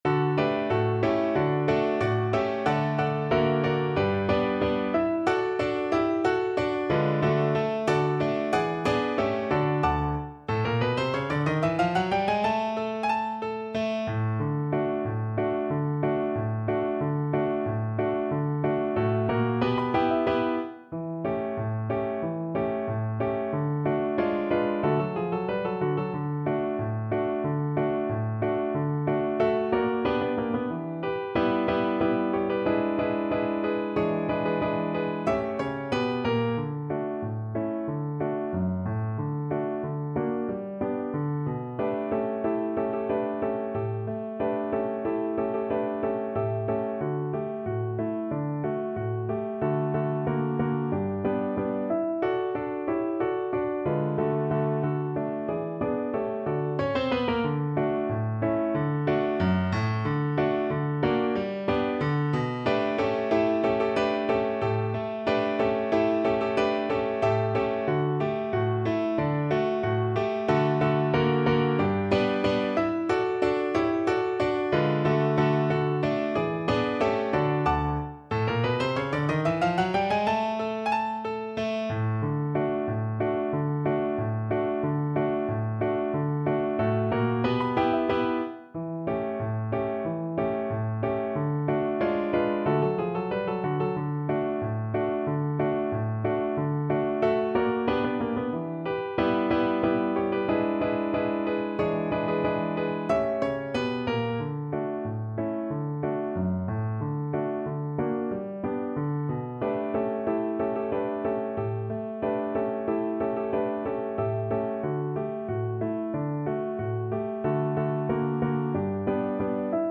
Allegretto =92